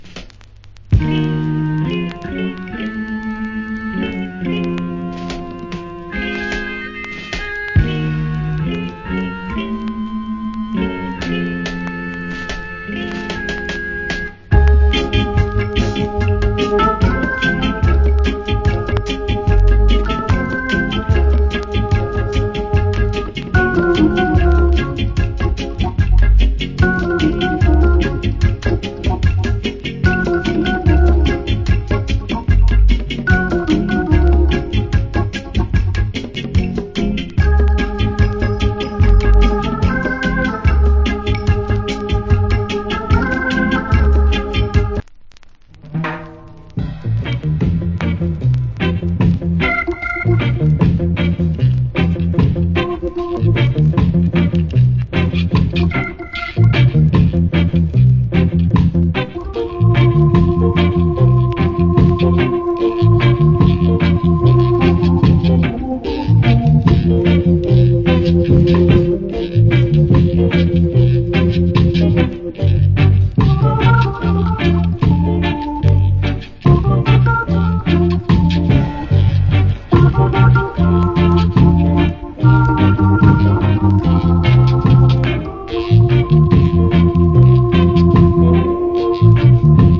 Cool Organ Reggae Inst.